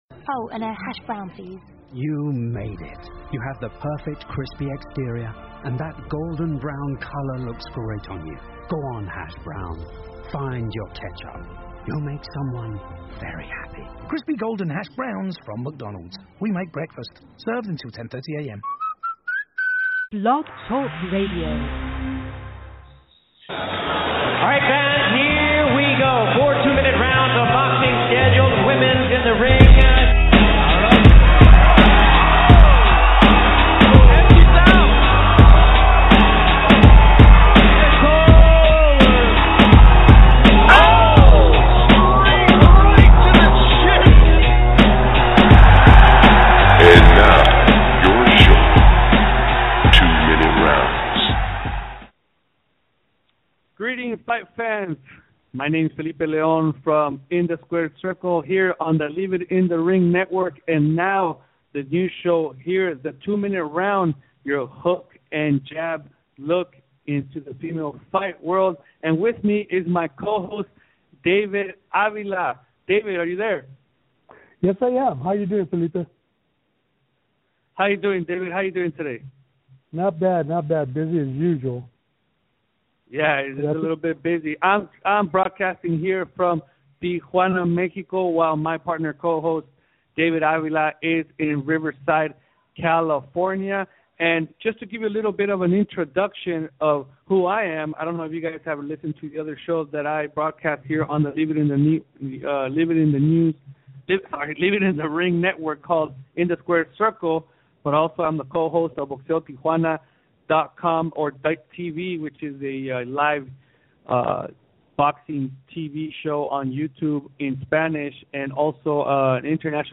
With updates and reviews on upcoming fights along with one-on-one interviews with fighters and all involved in women's boxing .